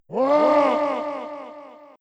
roar_GiRSRh7.mp3